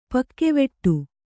♪ pakkeveṭṭu